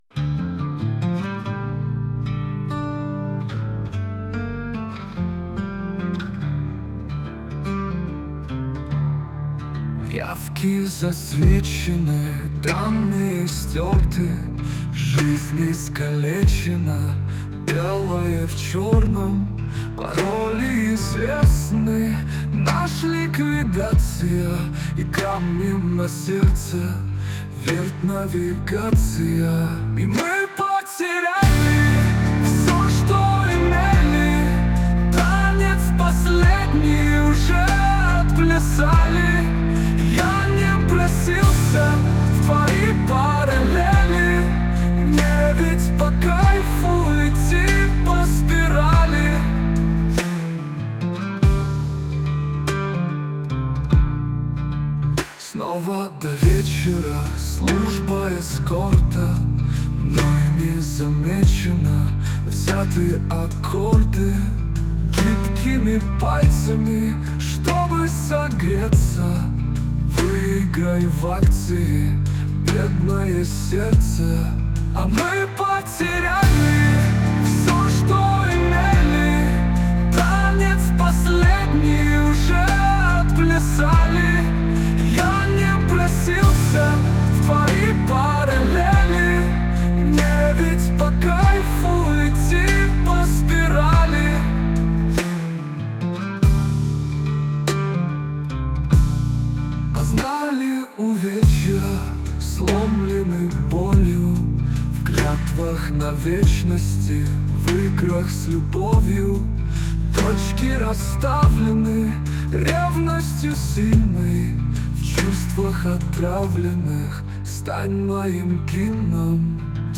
СТИЛЬОВІ ЖАНРИ: Ліричний
ВИД ТВОРУ: Пісня